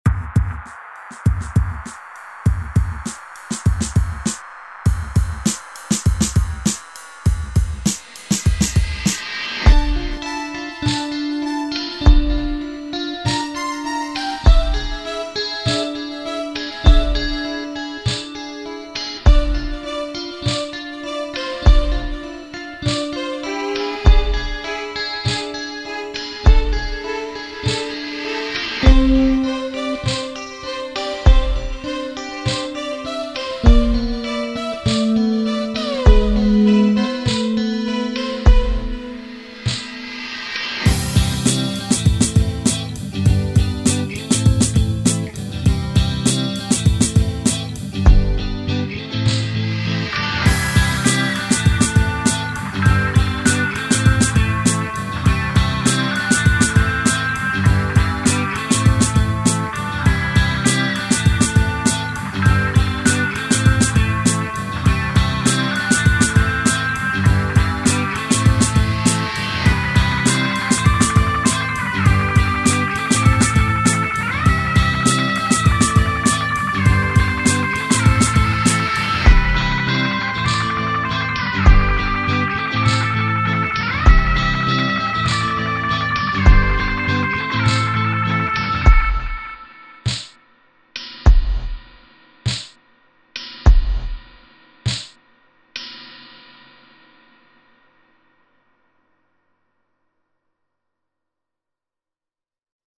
Ведь фанатская музыка, наверное, писалась под впечатлением музыки Ямаоки.